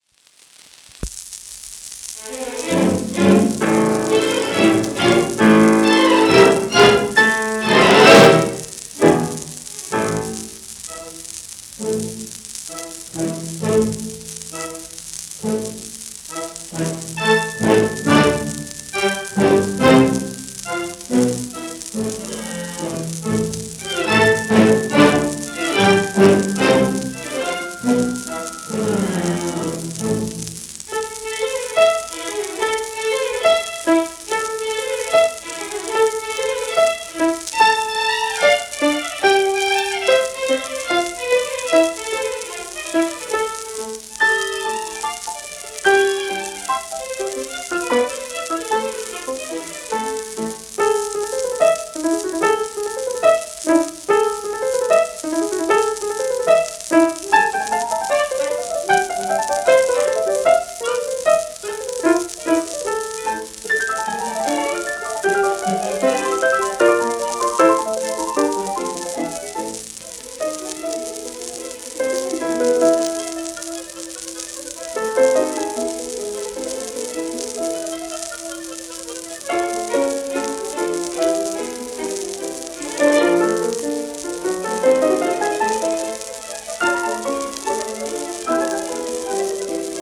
1934年録音